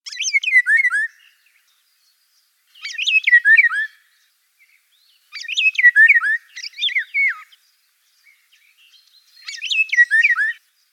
Звуки иволги
Такие звуки издает иволга